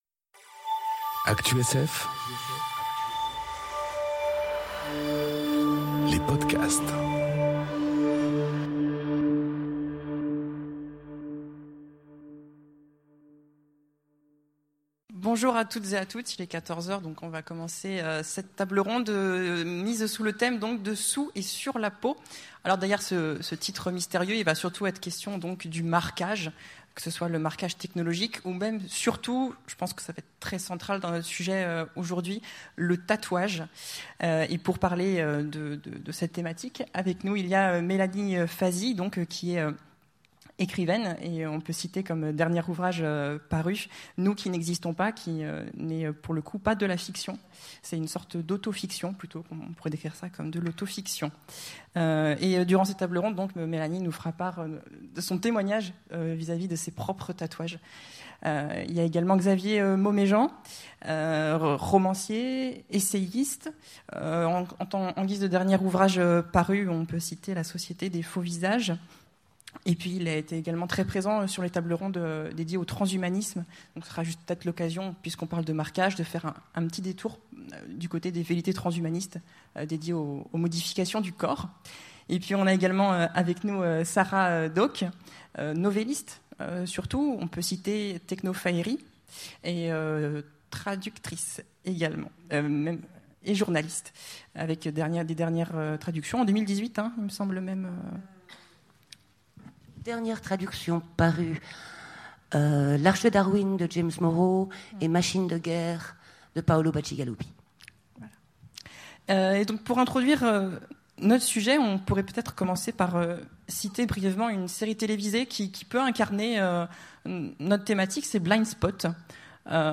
Conférence Sous/sur la peau enregistrée aux Utopiales 2018